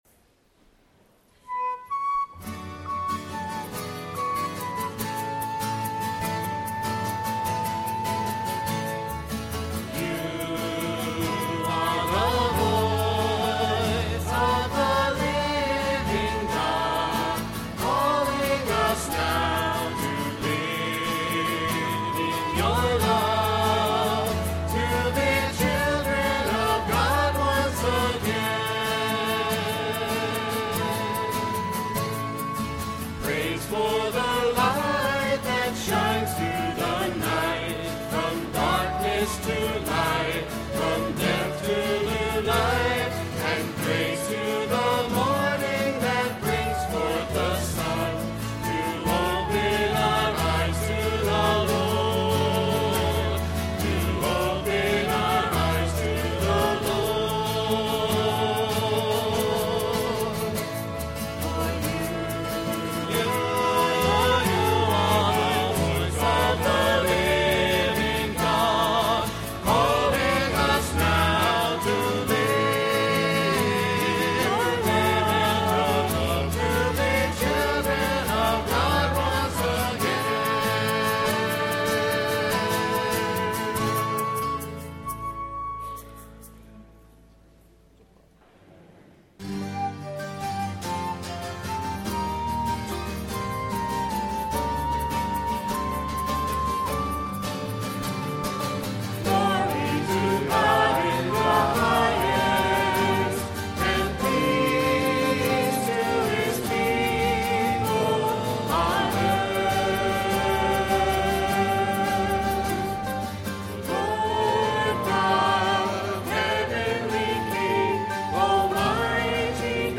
09/27/09 10:30 Mass Recording of Music - BK1030
Music from the 10:30 Mass on Sunday, September 27, 2009: Note that all spoken parts of the Mass have been removed from this sequence.